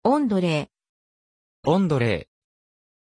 Pronuncia di Ondrej
pronunciation-ondrej-ja.mp3